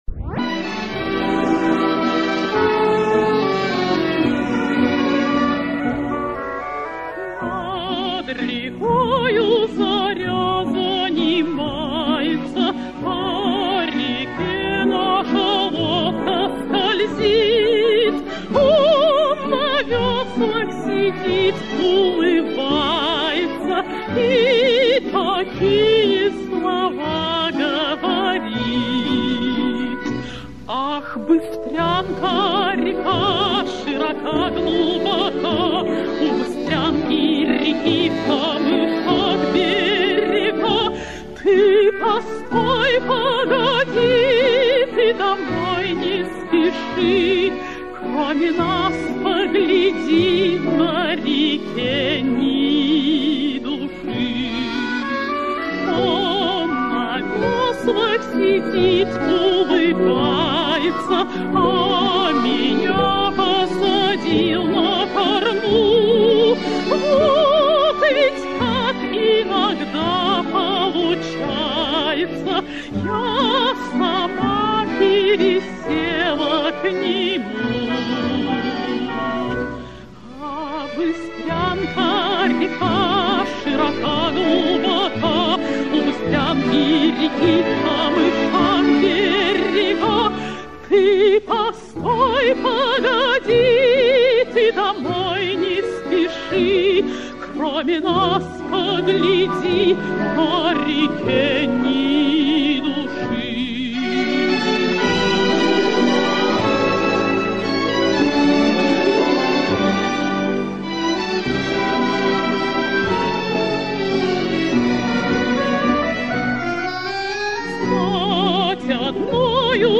Достаточно удачная лирическая песня в красивом исполнении.